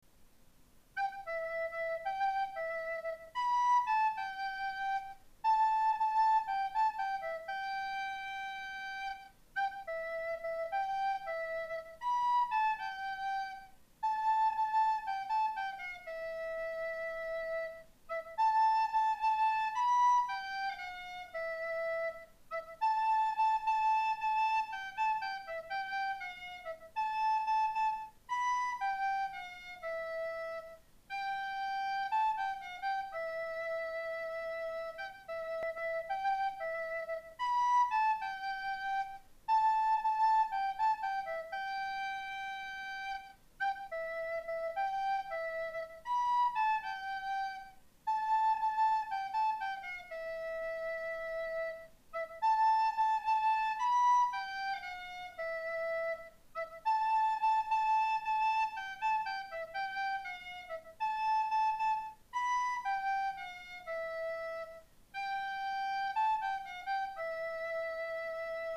בקובץ עצמו נגנתי על חלילית בלבד